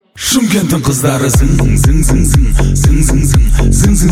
• Качество: 320, Stereo
мужской вокал
club